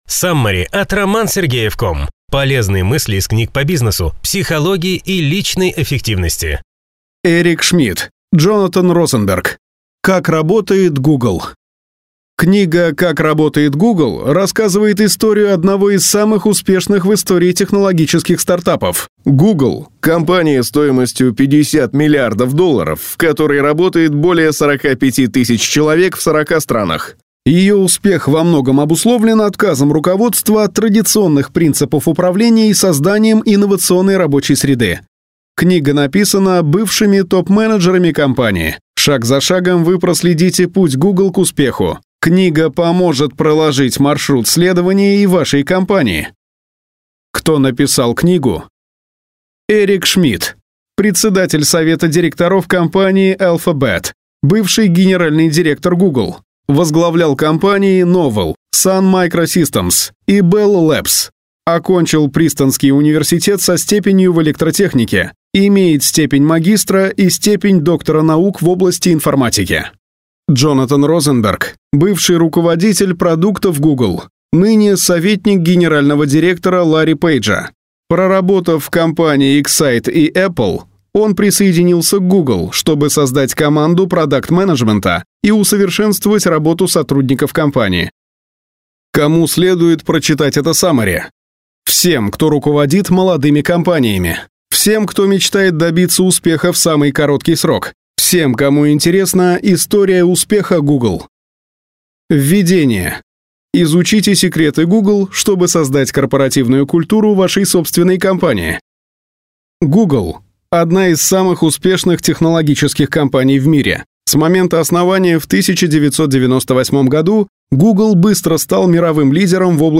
Аудиокнига Саммари на книгу «Как работает Google».